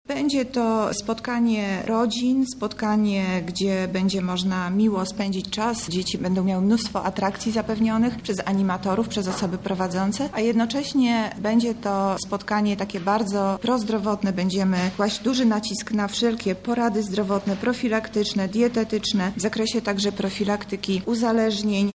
O Rodzinnym Festynie Zdrowotnym, który odbędzie się już we wtorek na placu przed Centrum Kultury mówi Monika Lipińska, zastępca prezydenta miasta.